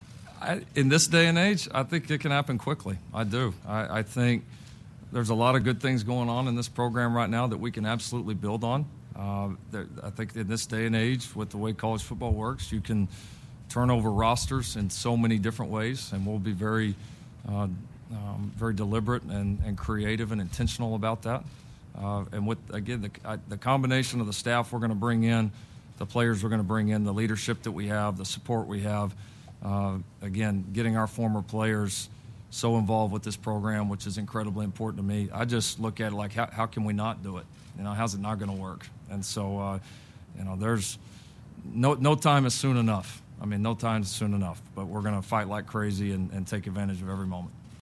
The former Oklahoma coach is officially the No. 18 coach in Trojans history after an introductory press conference Monday. Here, the 38-year-old discusses his vision for the program, including his championship aspirations.